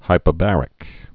(hīpə-bărĭk)